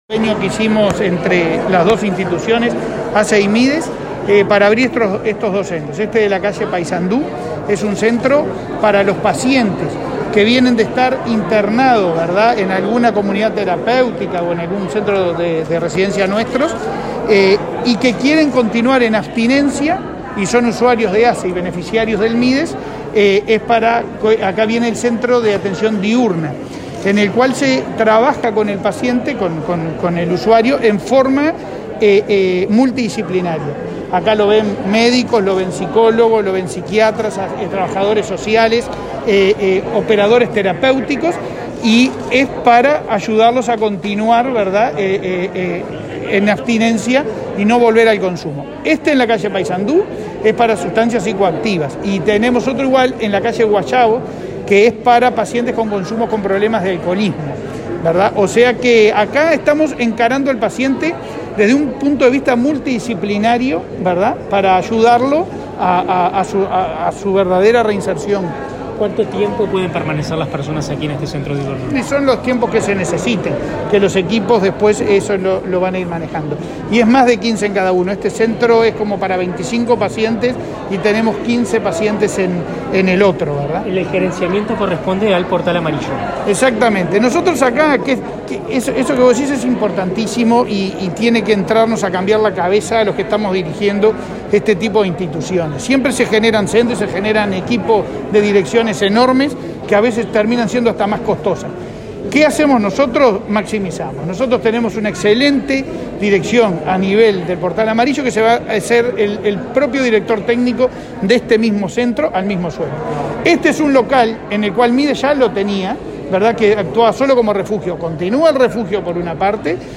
Declaraciones a la prensa del presidente de ASSE, Leonardo Cipriani
Declaraciones a la prensa del presidente de ASSE, Leonardo Cipriani 04/04/2022 Compartir Facebook X Copiar enlace WhatsApp LinkedIn El presidente de la Administración de los Servicios de Salud del Estado (ASSE), Leonardo Cipriani, participó este lunes 4, en Montevideo, en la inauguración de dos centros diurnos para personas que padecen consumo problemático de drogas. Luego, dialogó con la prensa.